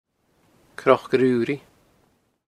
Name in Original Source: Cnoc a' Roo-ree